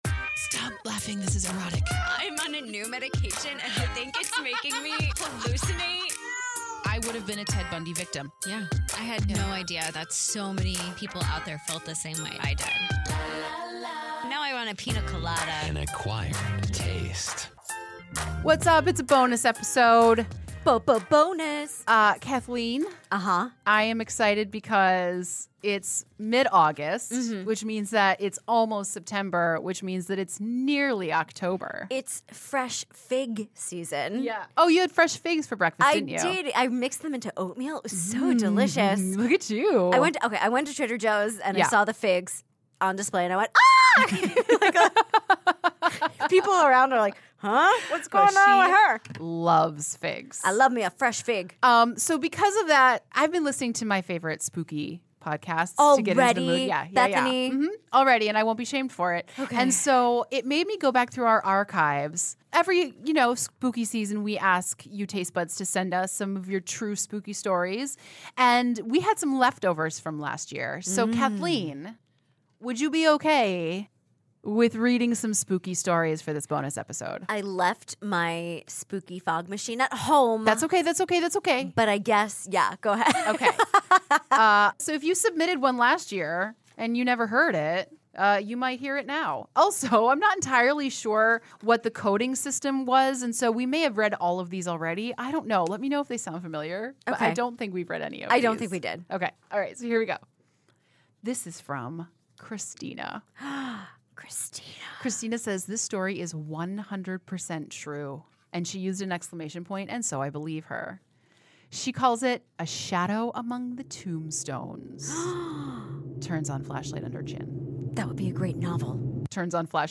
The ladies read two of your spooky emails leftover from last year.